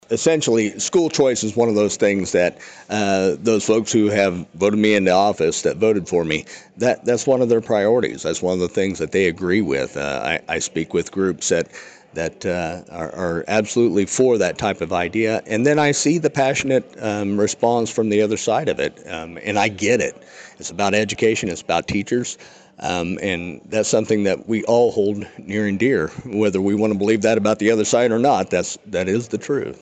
With the end of the 2023 regular legislative session approaching, local lawmakers were able to offer updates on a variety of topics during the latest legislative dialogue at the Flint Hills Technical College main campus Saturday morning.